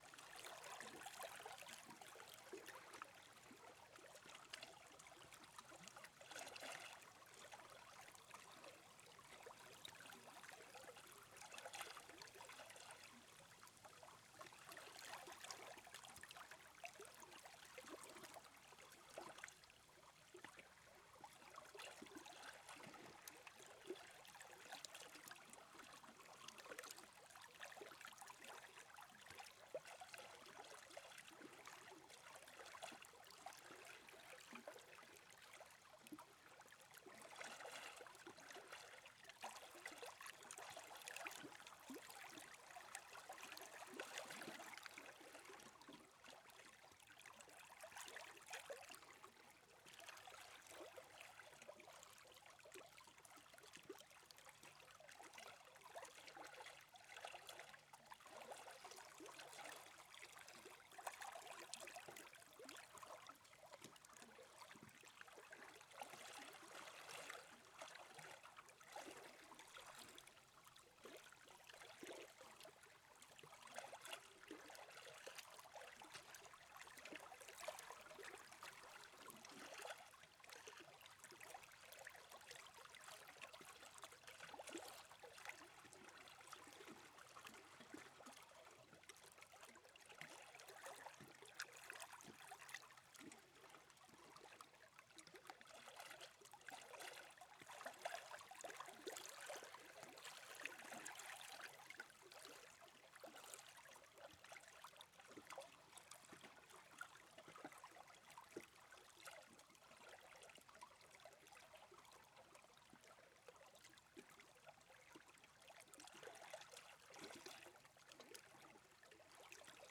Calm Water Lapping. Stereo. High-Quality 3-Minute Seamless Loop.
Category WATER Subcategory WAVE Location Hofsnäs - Karlavik Recorded 2025-10-27 16:24:26 Duration 180 sec Loopable Yes Download MP3